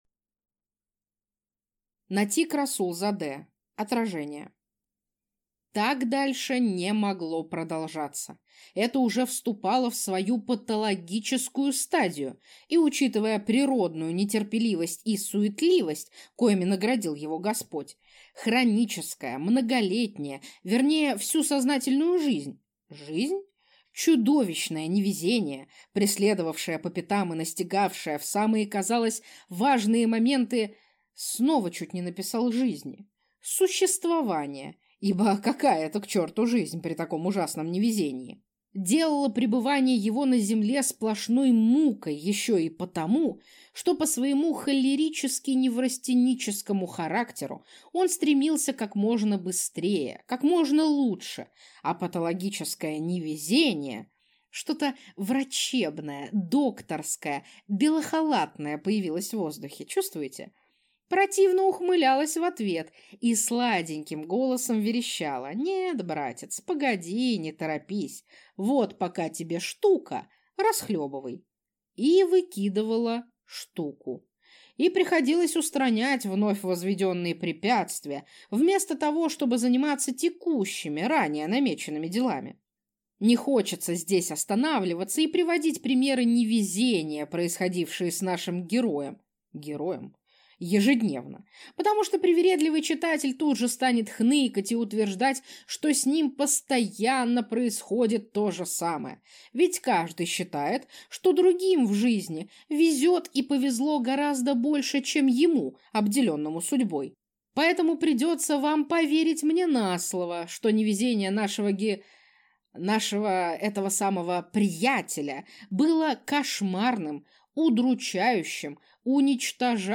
Аудиокнига Отражения | Библиотека аудиокниг